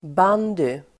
Ladda ner uttalet
bandy substantiv, bandy Uttal: [b'an:dy] Böjningar: bandyn Definition: ett lagspel på skridsko med klubba och boll (a team sport played on skates with a stick and a ball) Sammansättningar: bandy|klubba (bandy stick)